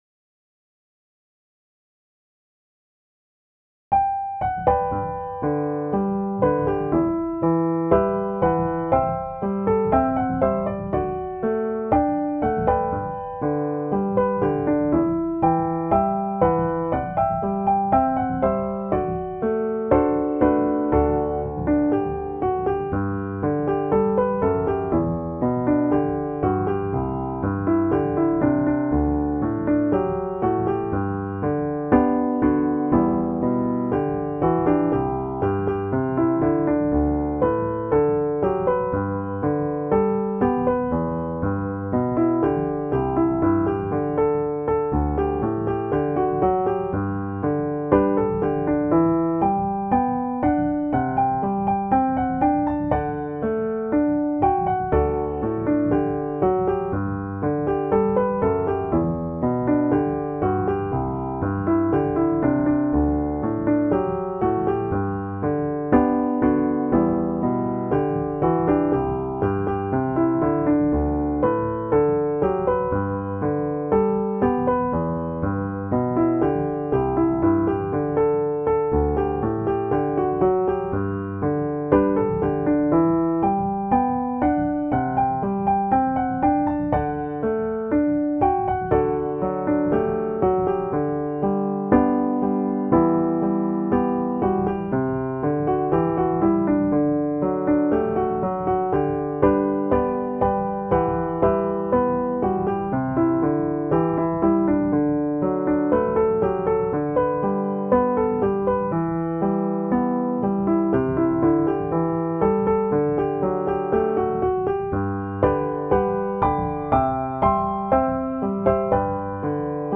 سبک موسیقی: کانتری
گام: G Major
ریتم : 4/4
تمپو: 120